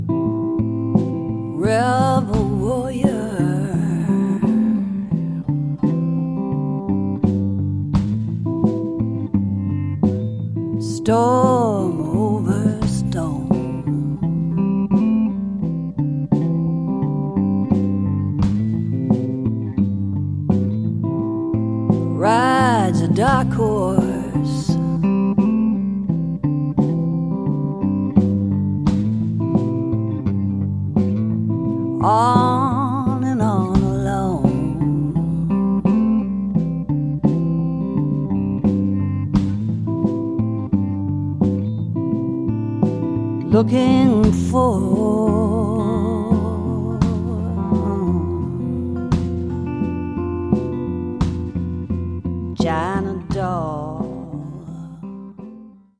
Drums
Vocals, Guitar